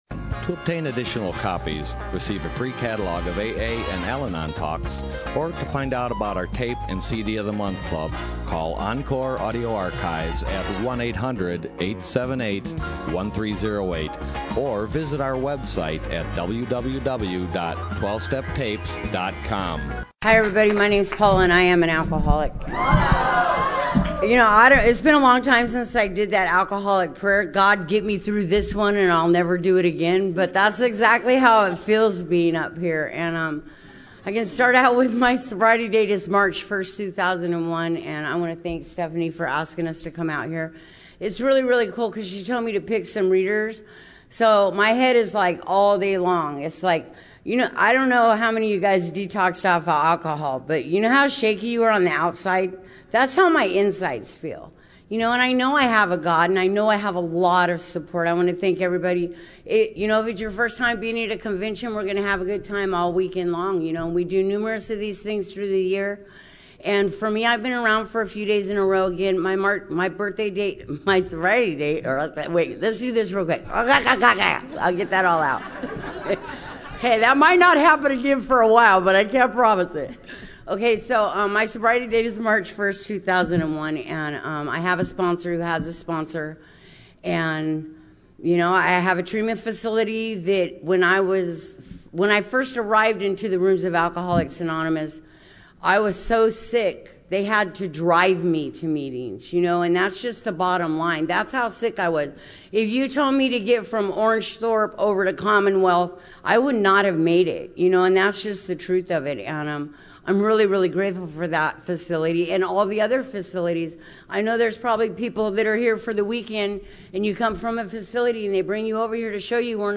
Orange County AA Convention 2014